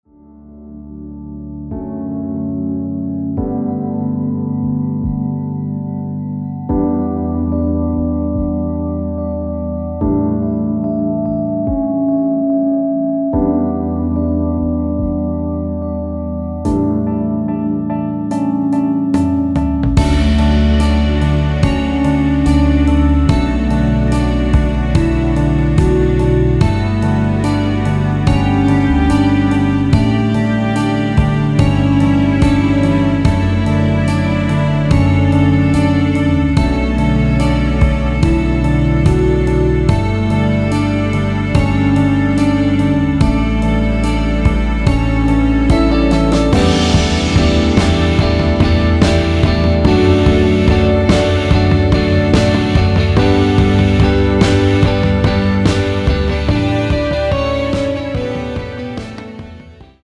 원곡 6분1초에서 4분 41초로 짧게 편곡된 MR입니다.
앞부분30초, 뒷부분30초씩 편집해서 올려 드리고 있습니다.